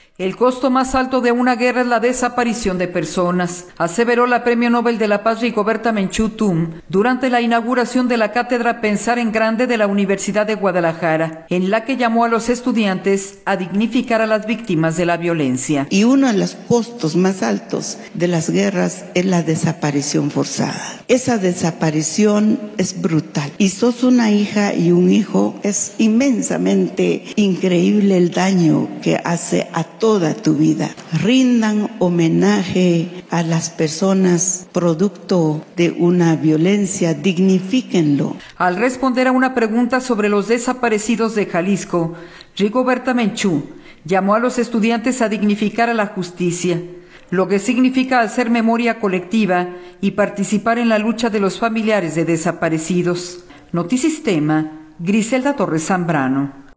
El costo más alto de una guerra es la desaparición de personas, aseveró la Premio Nobel de la Paz, Rigoberta Menchú Tum, durante la inauguración de la Cátedra Pensar en Grande de la Universidad de Guadalajara, en la que llamó a los […]